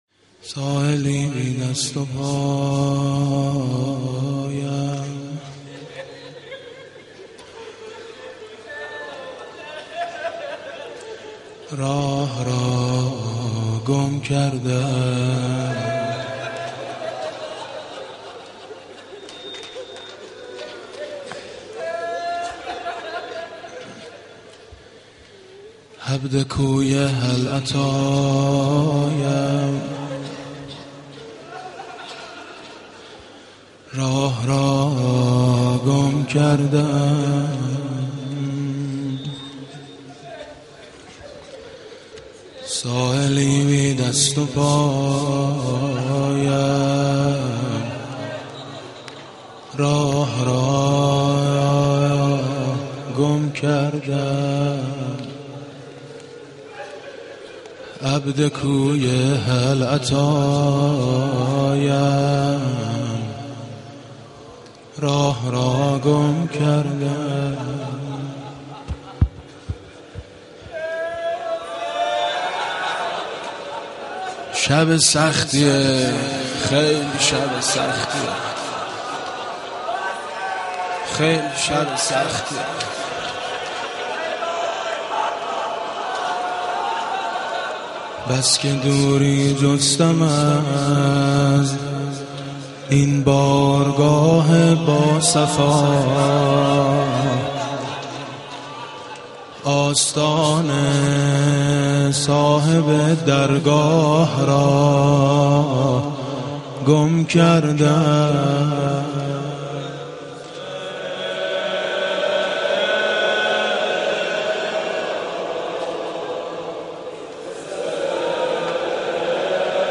مناسبت : شب دوم محرم